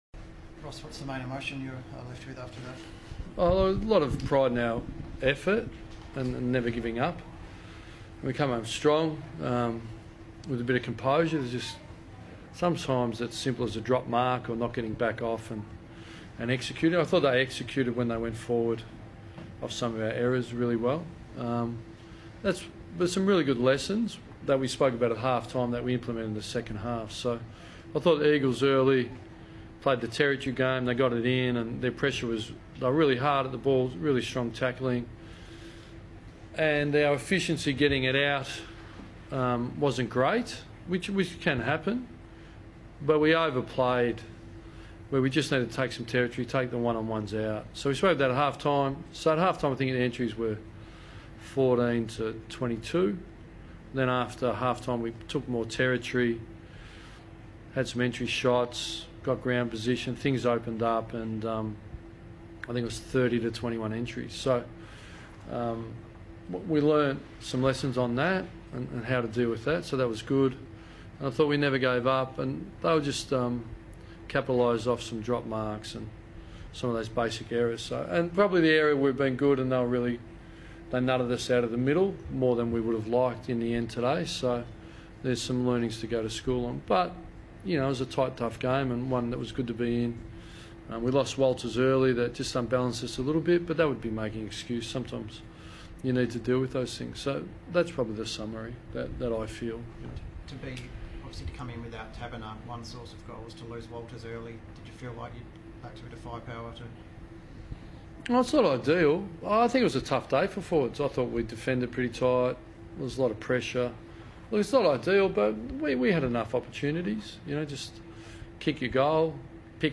Ross Lyon post-match Q&A - Round 6 v West Coast Eagles
Watch Ross Lyon's media conference following the loss to West Coast.